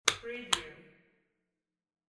Handbag Lock Wav Sound Effect #6
Description: The sound of a handbag lock snapping open or close (two clicks)
Properties: 48.000 kHz 16-bit Stereo
Keywords: handbag, purse, bag, lock, locking, unlocking, snap, click, open, opening, close, closing, shut, shutting, latch, unlatch
handbag-lock-preview-6.mp3